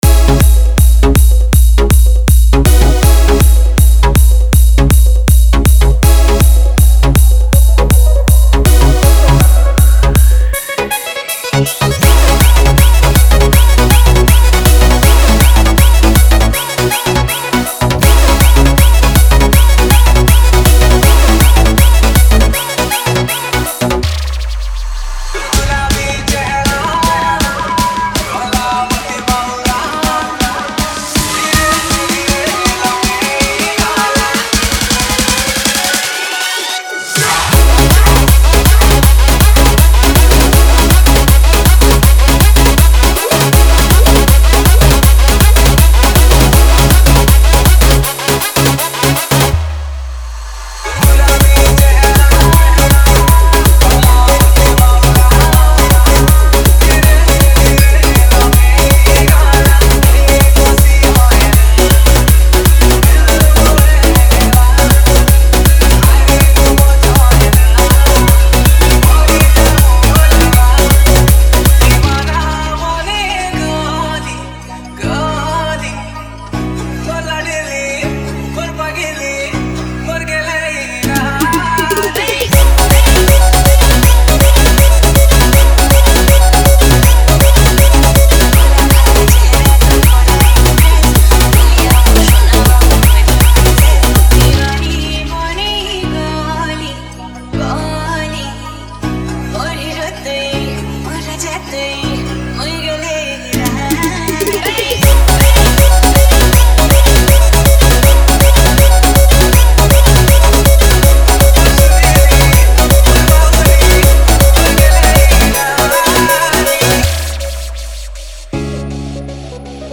(Dance Rmx)